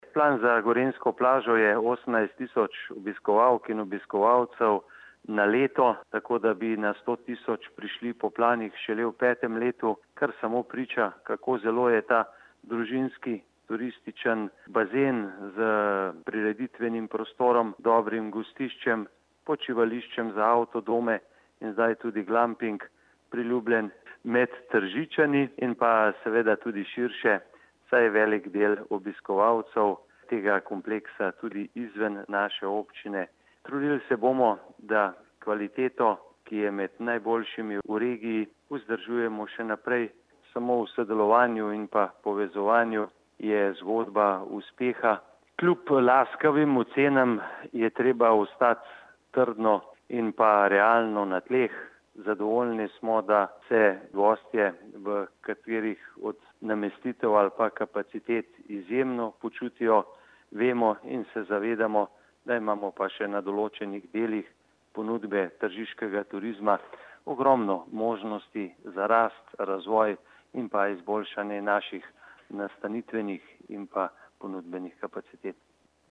izjava_zupanobcinetrzicmag.borutsajovicogorenjskiplazi.mp3 (1,8MB)